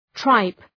{traıp}